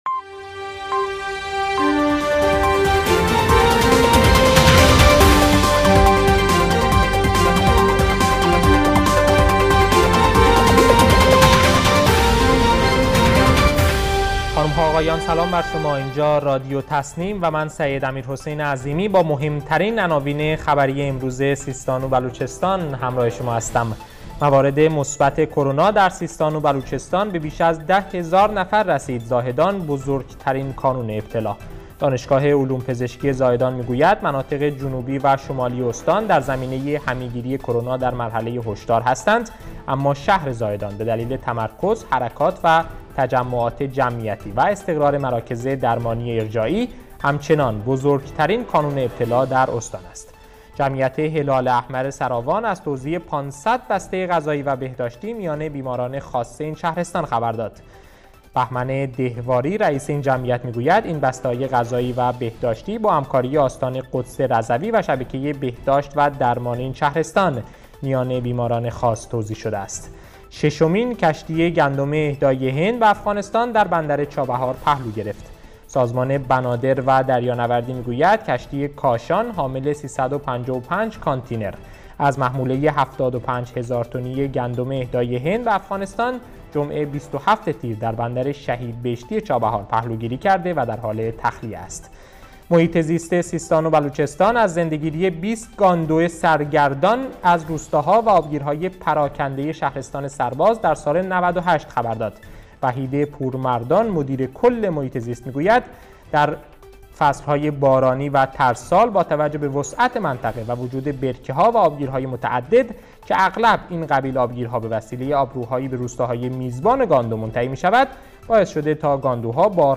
گروه استان‌ها ـ در اولین بسته خبری رادیو تسنیم سیستان و بلوچستان با مهم‌ترین عناوین خبری امروز همراه ما باشید.